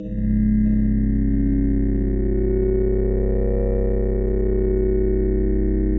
科幻无人机 " 无人机01
描述：科幻无人机，用于室内或室外房间的音调，气氛，外星人的声音，恐怖/期待的场景.
Tag: 外来 大气压 无人驾驶飞机 音响 恐怖 roomtones SCI